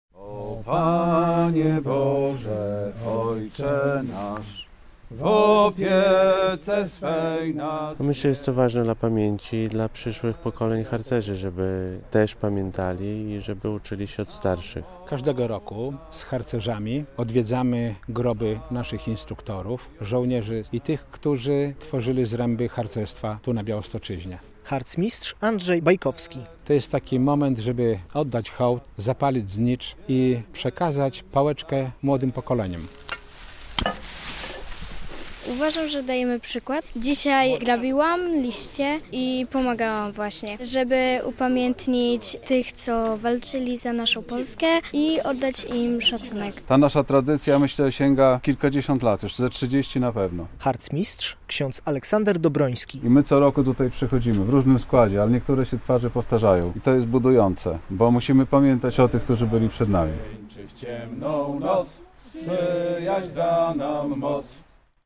Harcerze odwiedzili groby zasłużonych druhów - relacja